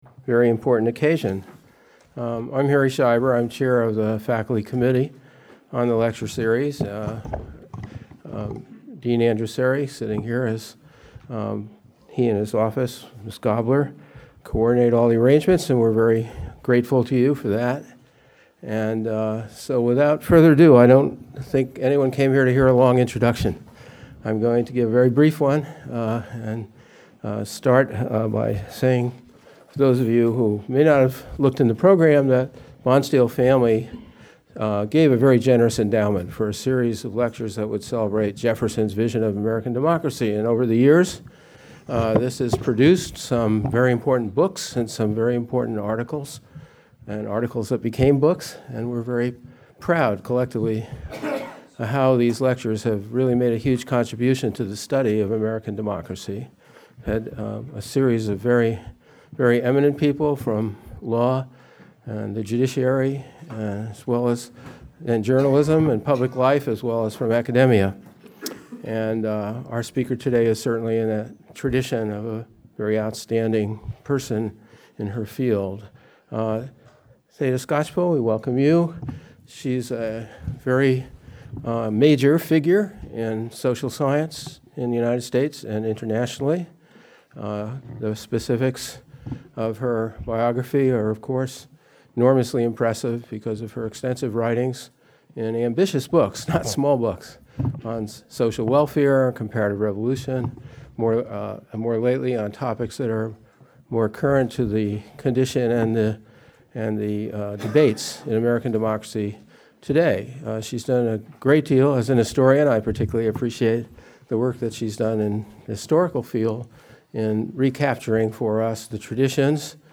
Alumni House, Toll Room — UC Berkeley Campus